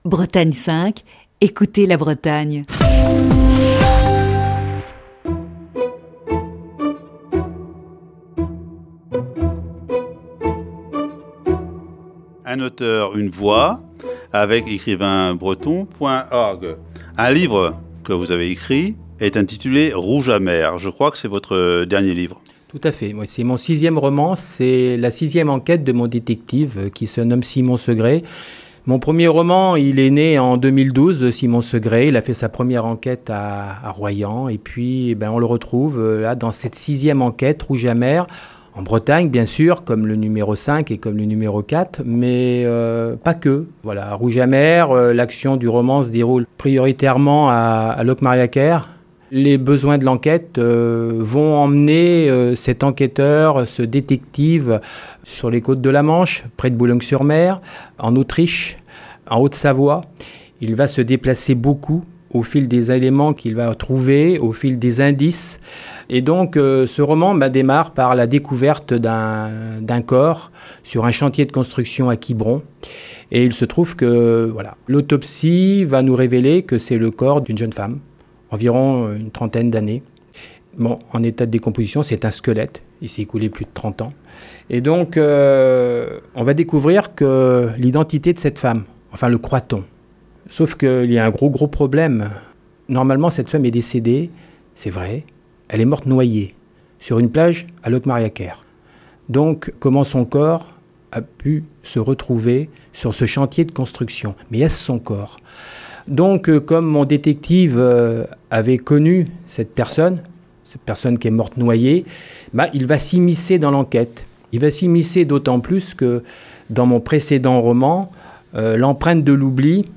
Voici ce vendredi la cinquième et dernière partie de cet entretien.